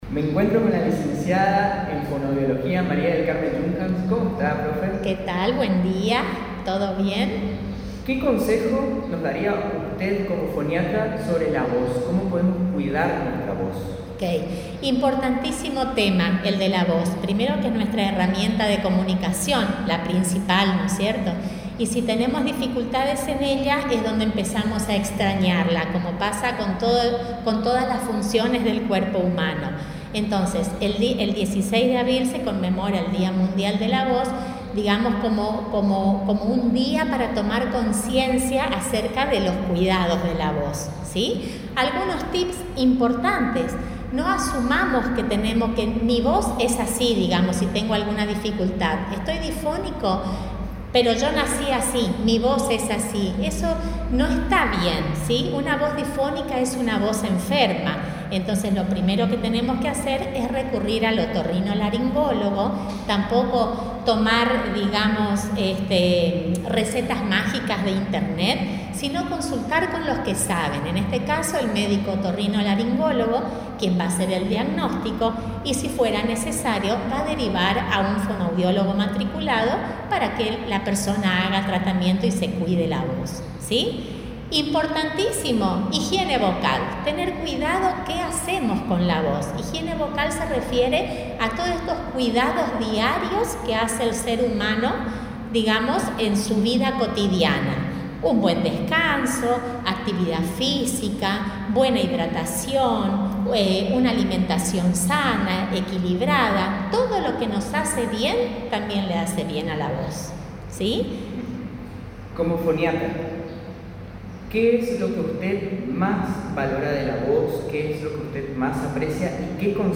Entrevistas
El audio de la entrevista compartida en Radio Tupambaé: